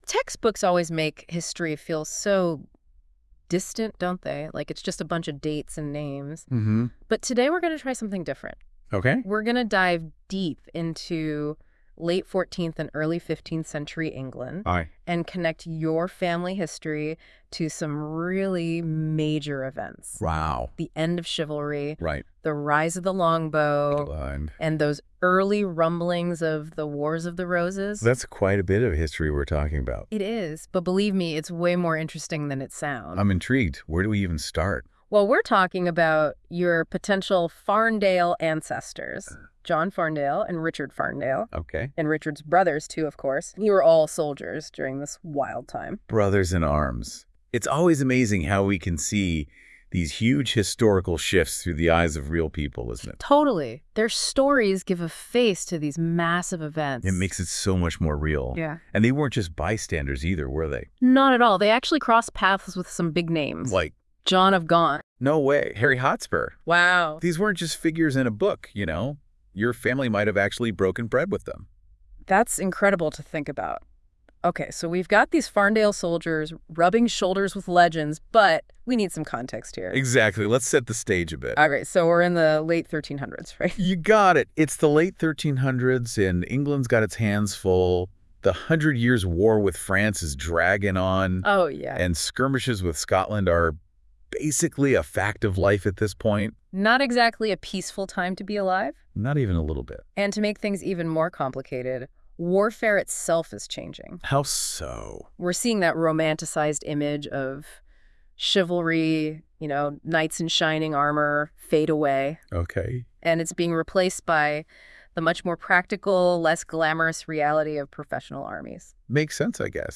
Using Google�s Notebook LM, listen to an AI powered podcast summarising this page.